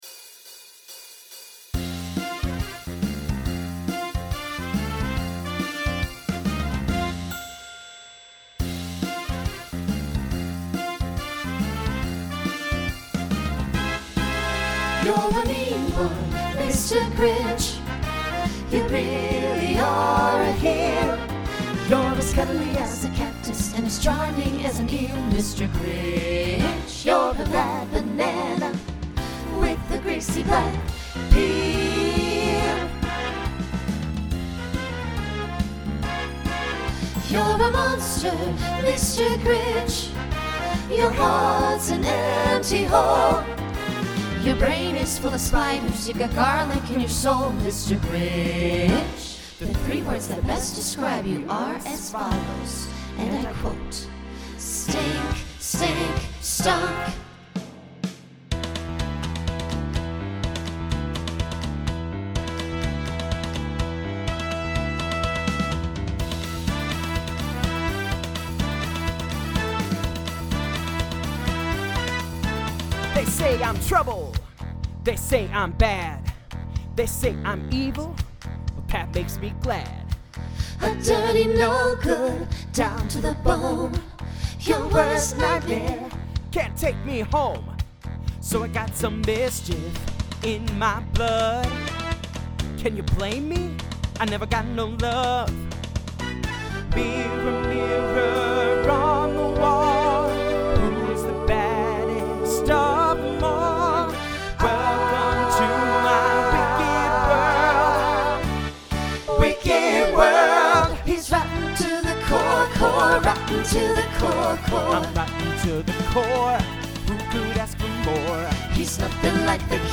Broadway/Film , Rock , Swing/Jazz
Voicing SATB